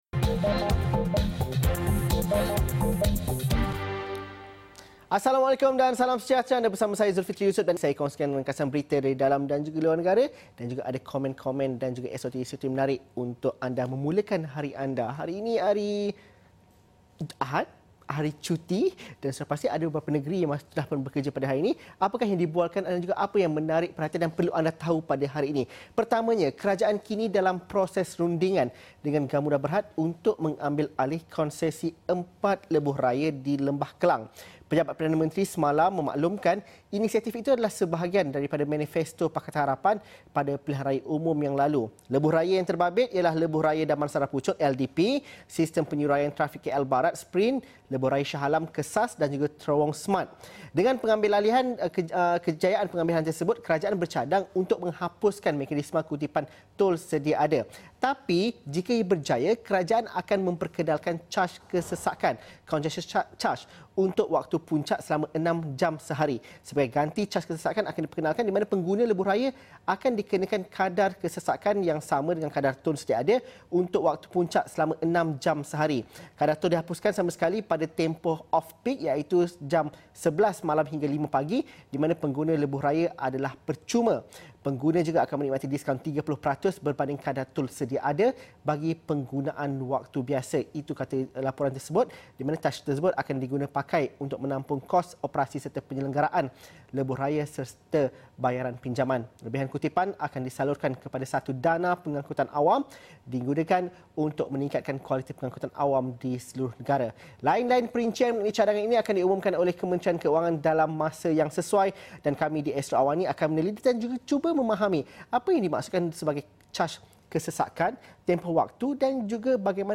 Kupasan 15 minit berita dan isu-isu semasa, dalam dan luar negara secara ringkas dan padat